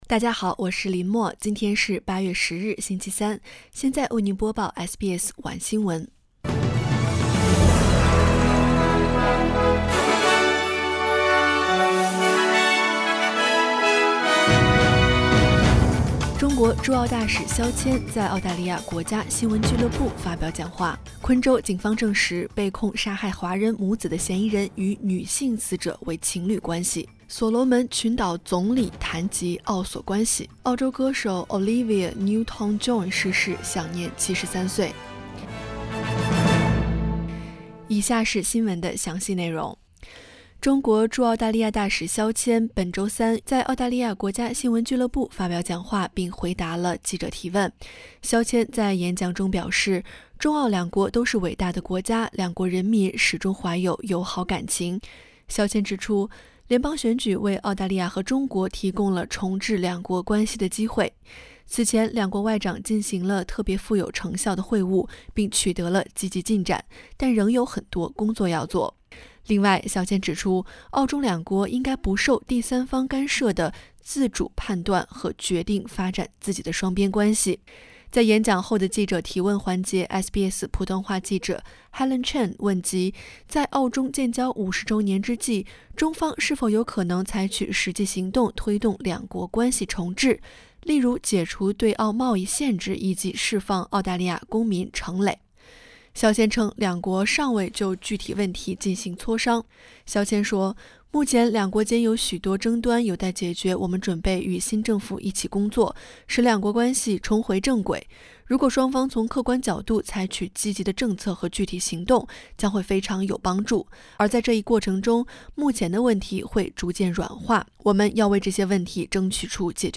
SBS晚新闻（2022年8月10日）